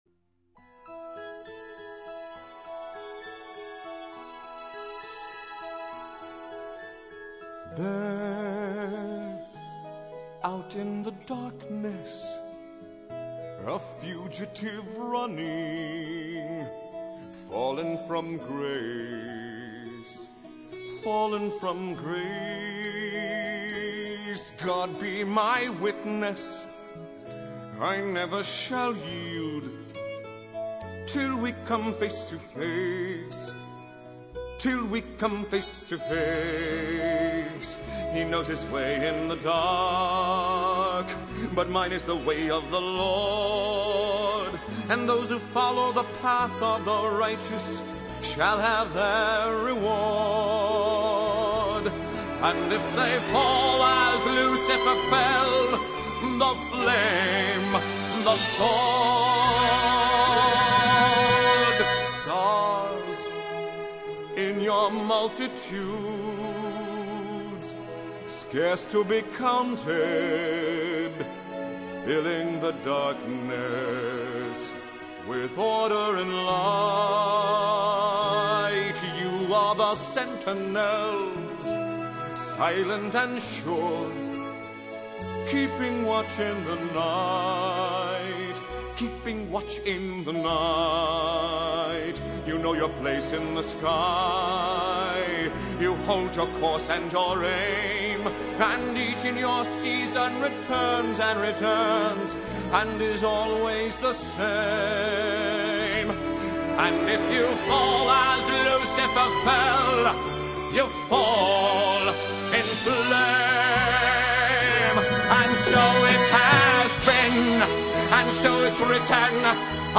4. 美國百老匯版：這是悲慘世界移師美國百老匯時的首演錄音版，基本上我把這個版本叫做電子琴花車版，有興趣的人